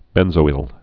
(bĕnzō-ĭl, -zoil)